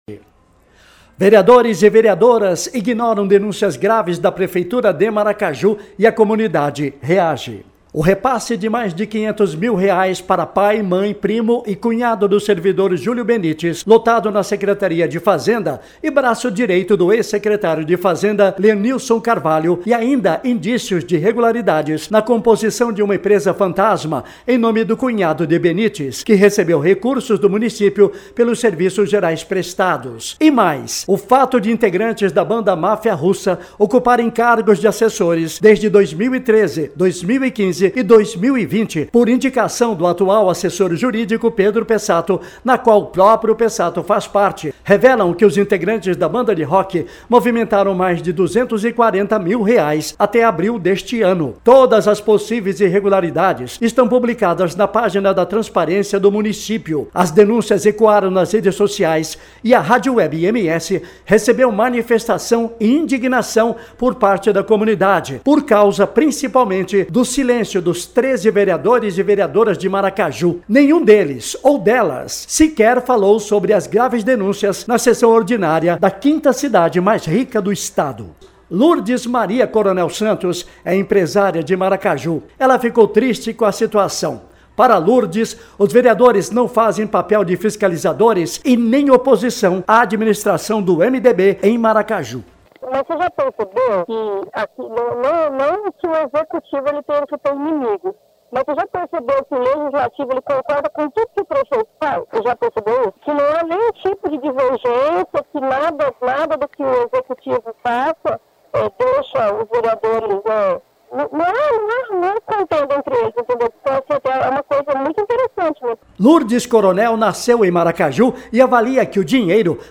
Já um trabalhador  que não quis se identificar mandou mensagem de áudio cobrando ação dos Vereadores,(as) e da imprensa Maracajuense.